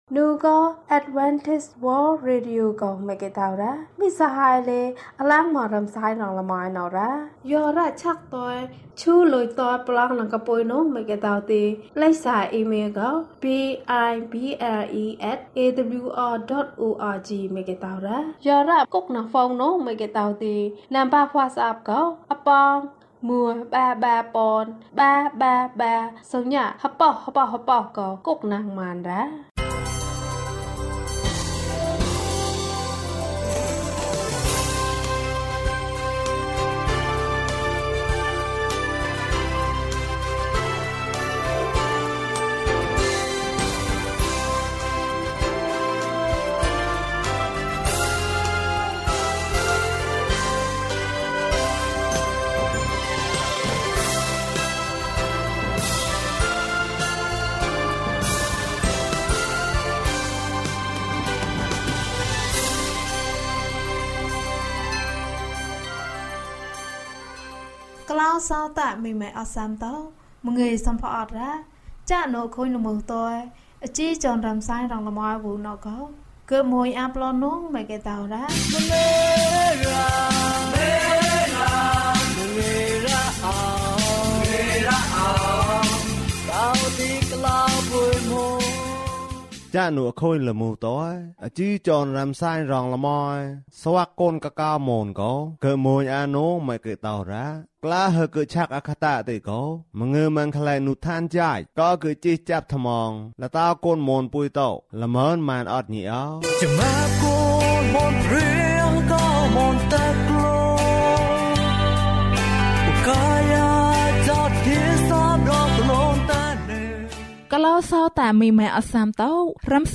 ယေရှုနှင့်အတူအရာအားလုံး။ ကျန်းမာခြင်းအကြောင်းအရာ။ ဓမ္မသီချင်း။ တရားဒေသနာ။